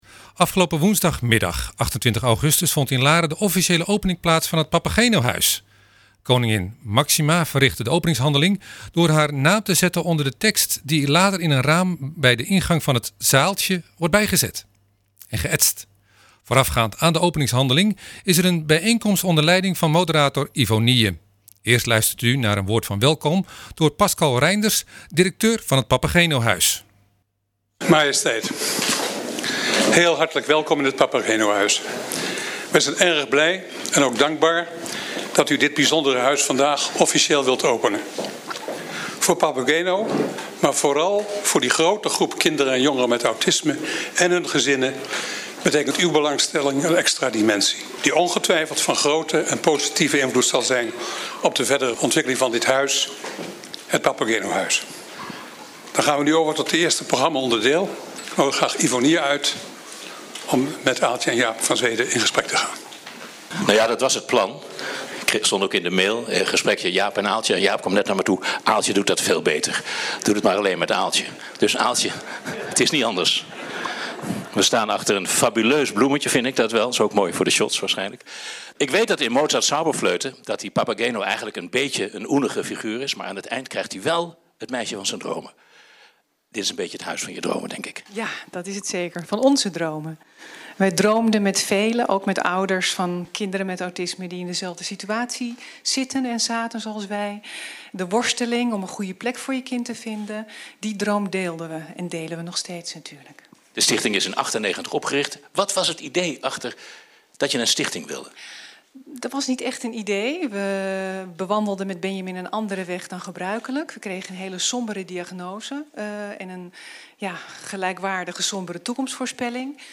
Radio 6FM was woensdagmiddag 26 augustus aanwezig bij de officiele opening van het Papagenohuis te Laren (NH) door H.M. Koningin Maxima.
bariton
Tot slot een interview met twee moeders